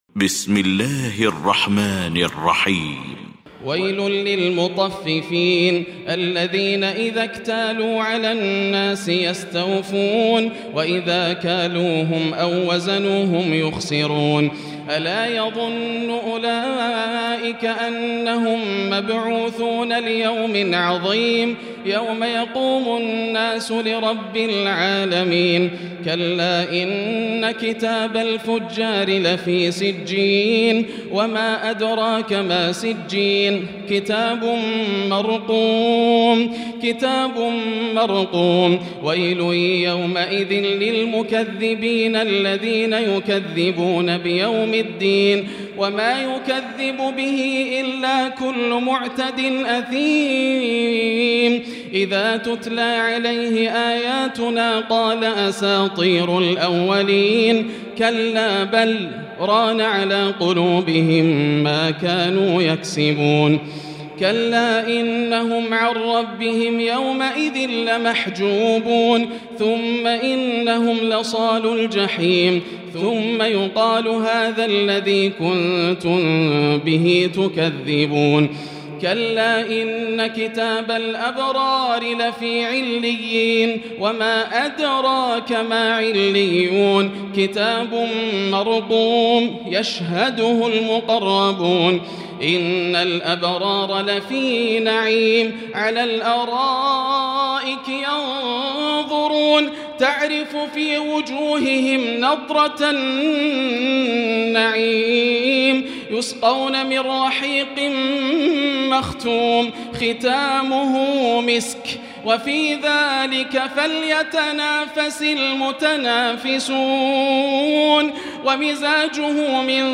المكان: المسجد الحرام الشيخ: فضيلة الشيخ ياسر الدوسري فضيلة الشيخ ياسر الدوسري المطففين